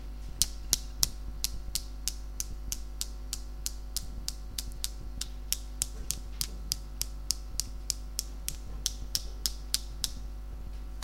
描述：用一支笔打另一支笔。